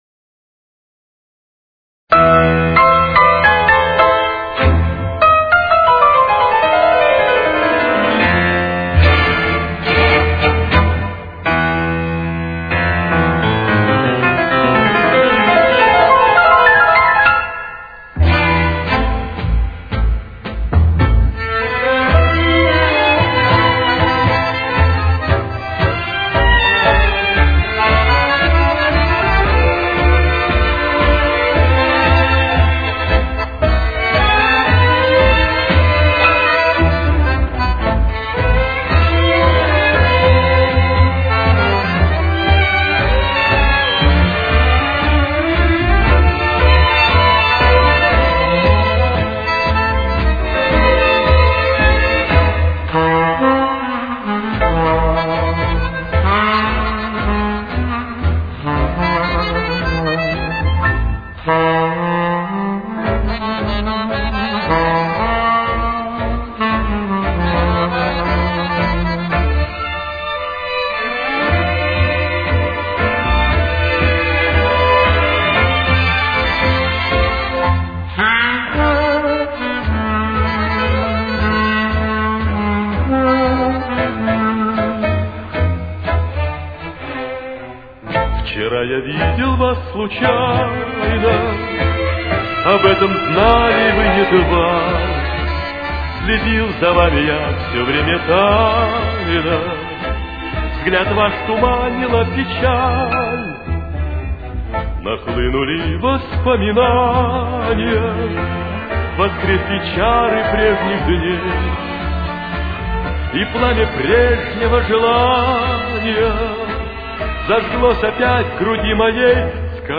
Темп: 117.